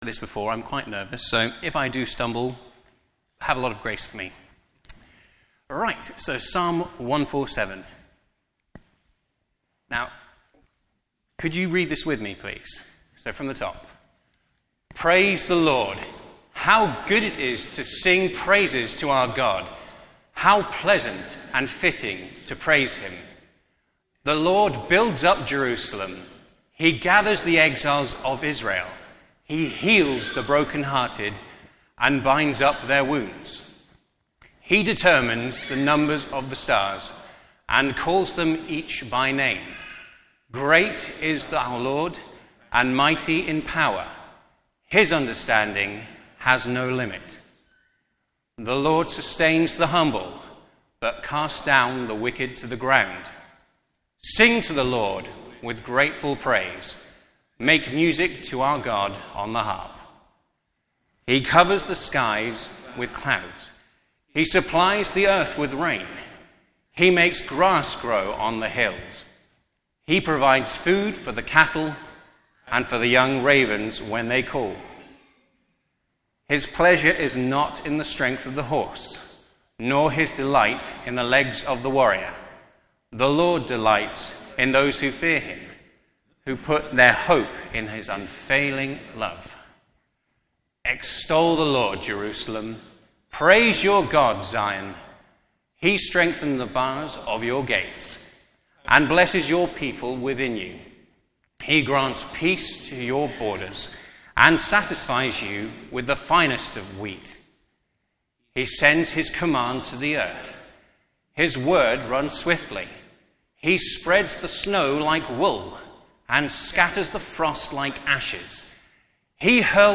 Media for Morning Service on Sun 17th Aug 2025 10:30 Speaker
Psalms Theme: Sermon In the search box please enter the sermon you are looking for.